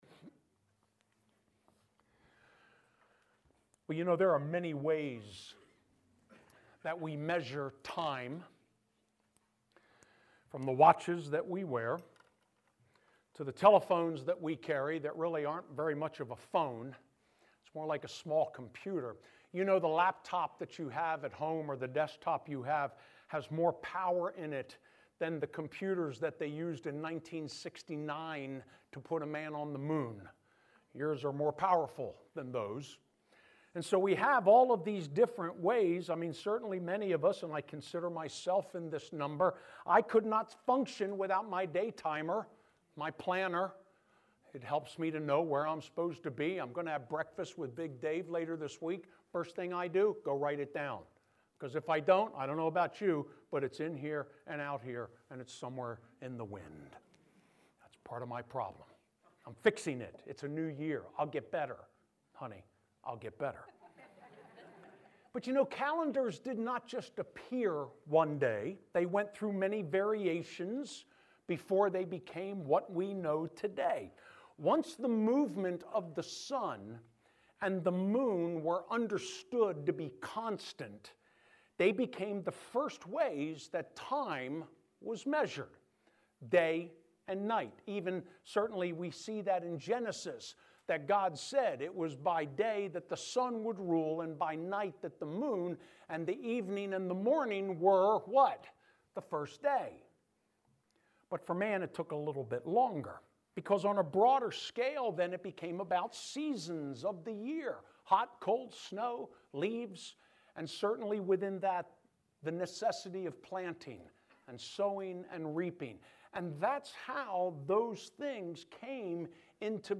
Remnant Life Church: Sermons